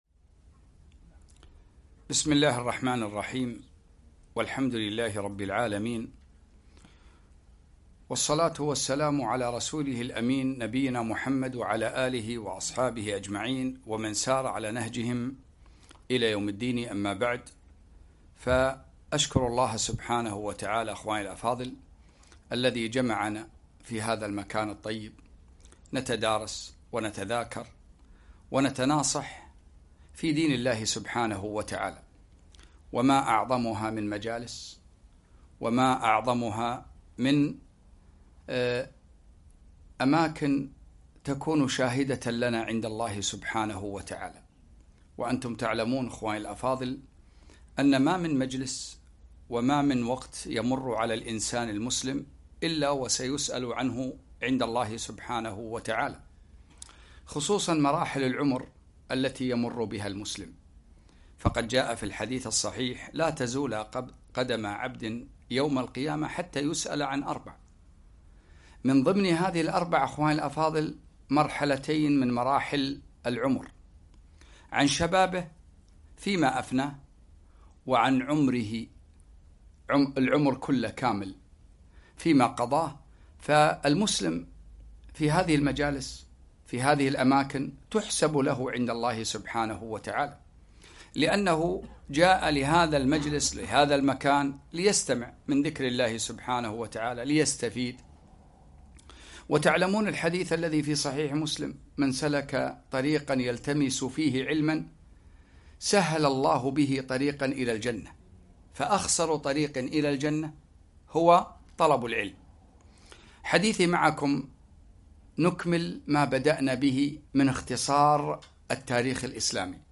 2- اختصار تاريخ المسلمين - دولة الخلفاء الراشدين في محاضرة واحدة